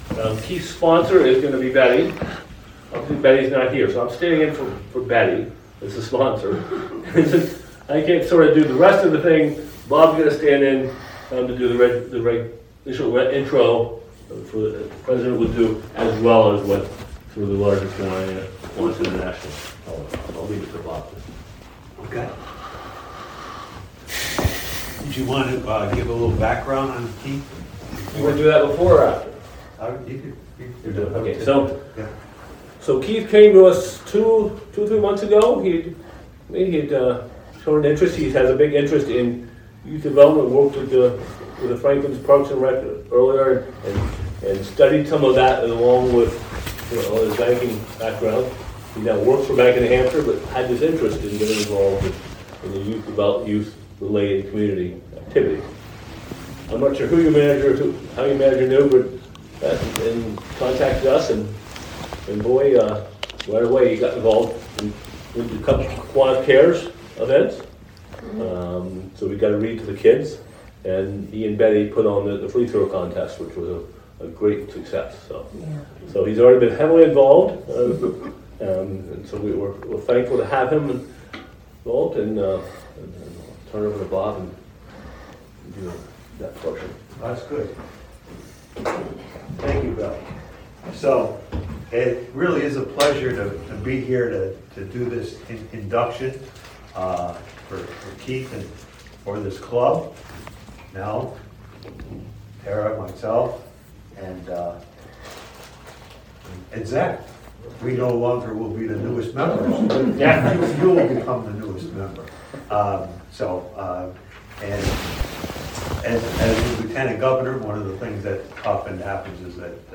INDUCTION OF NEW MEMBER